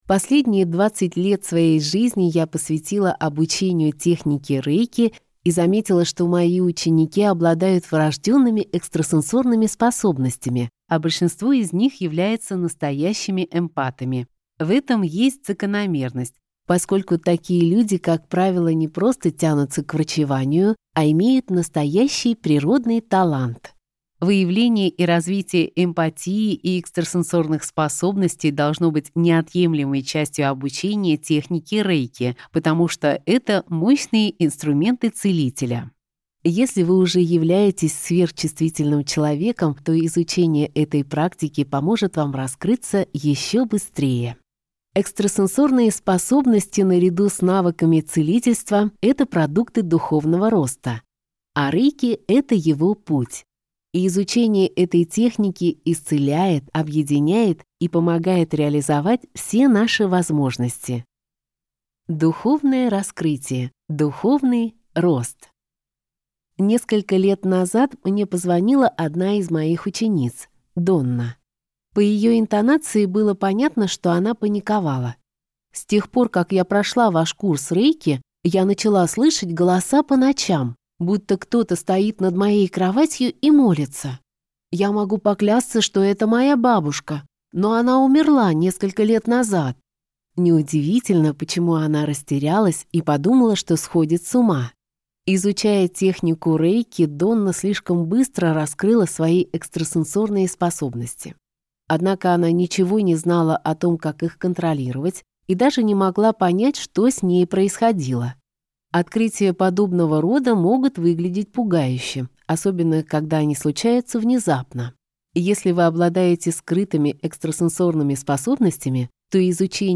Аудиокнига Рэйки: развитие интуитивных и эмпатических способностей для энергетического исцеления | Библиотека аудиокниг
Прослушать и бесплатно скачать фрагмент аудиокниги